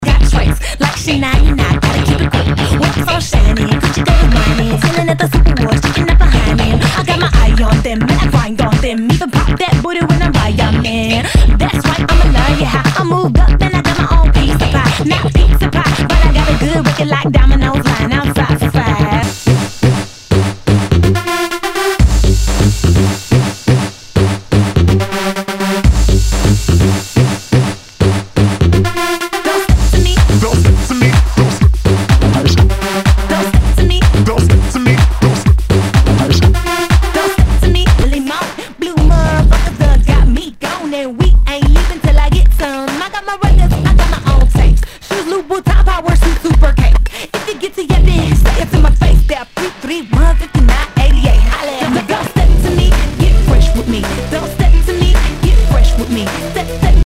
HOUSE/TECHNO/ELECTRO
ナイス！エレクトロ・ハウス！
全体にチリノイズが入ります